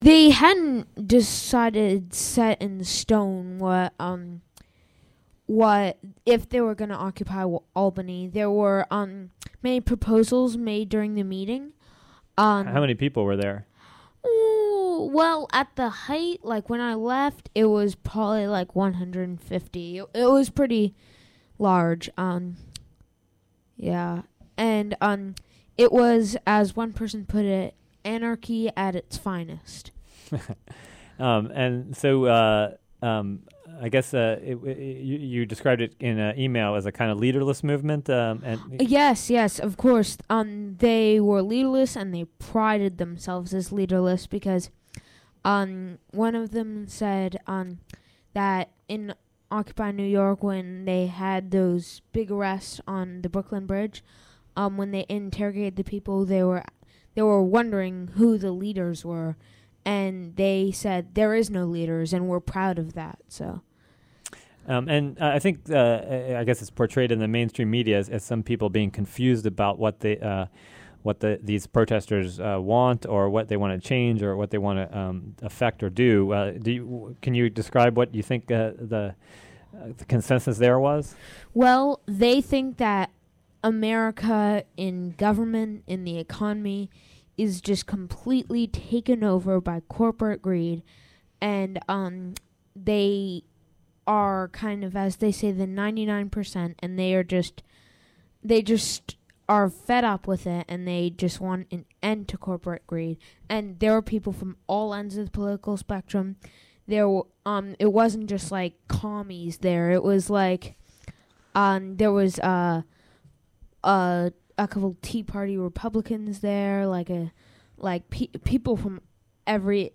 Occupy Albany News Report (Audio)